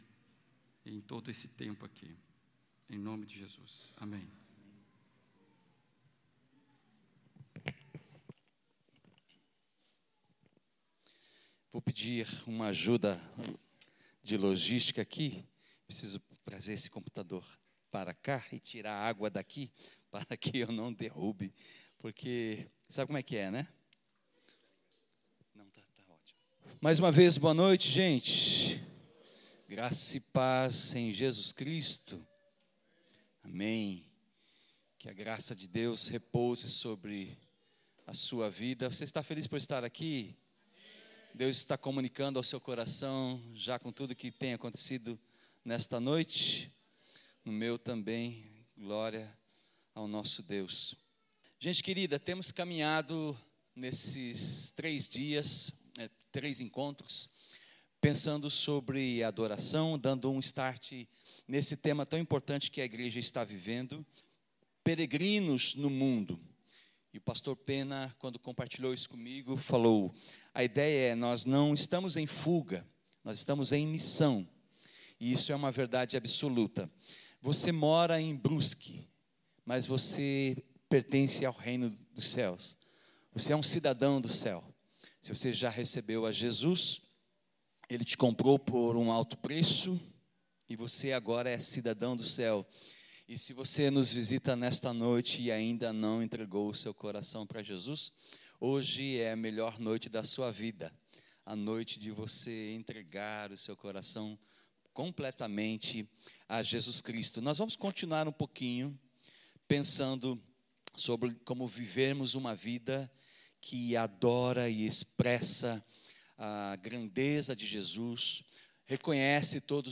O Perfeito Amor nos amou - PIB Brusque 28 anos